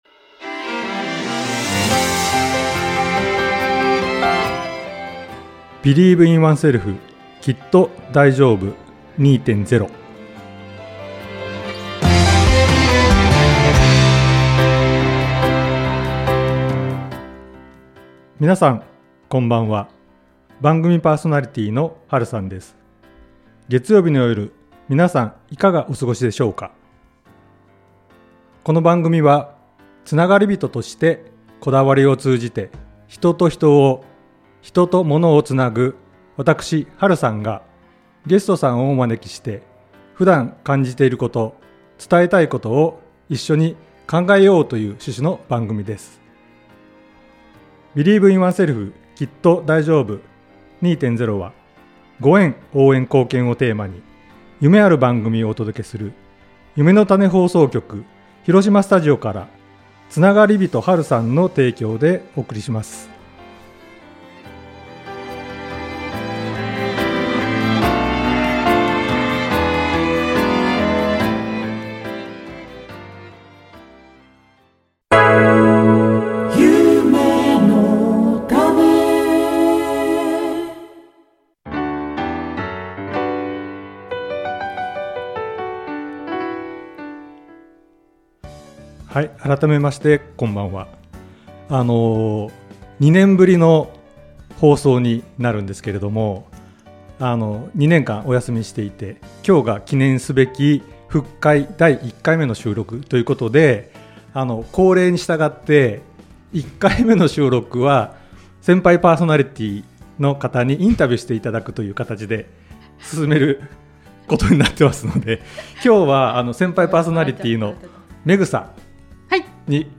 広島スタジオ